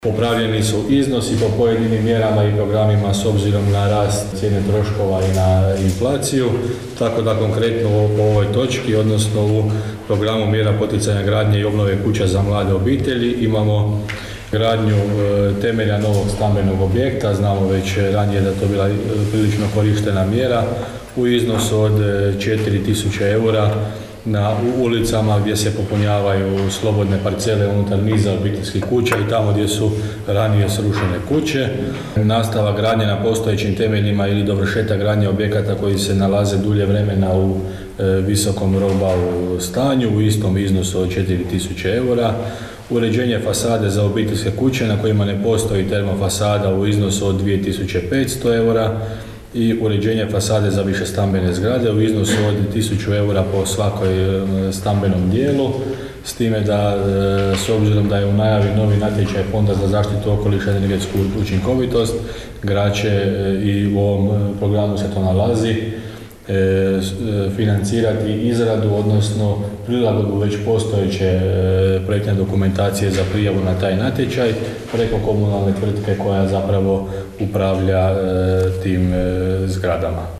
Među točkama dnevnog reda na održanoj Sjednici Gradskog vijeća Grada Đurđevca, našlo se 40ak mjera koje Grad Đurđevac ima za potpore mladim obiteljima, uređenje kuća i okućnica, poduzetništvo i potpore za poljoprivredu.